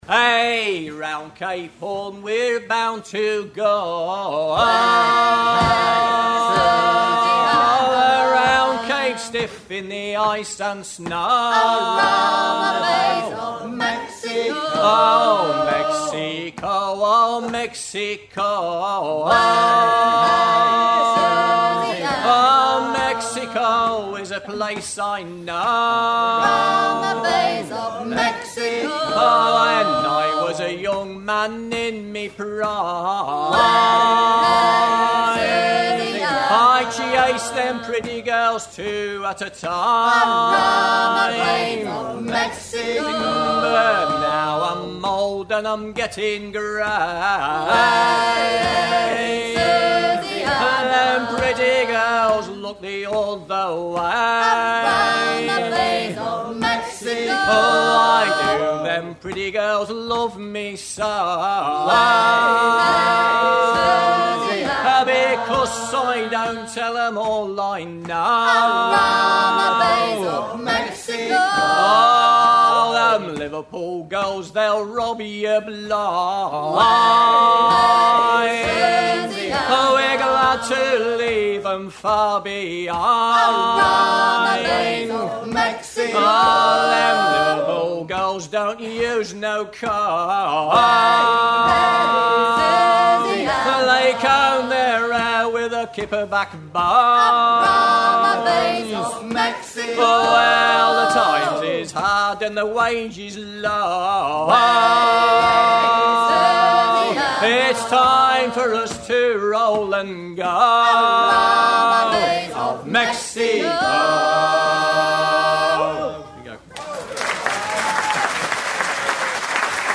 chantey
Pièce musicale éditée